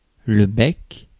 Ääntäminen
France: IPA: [bɛk]